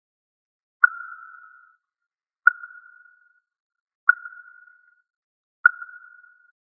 师兄听后觉得很摄心，表示就这样跟着木鱼声念佛，能减少妄念。
01.木鱼.mp3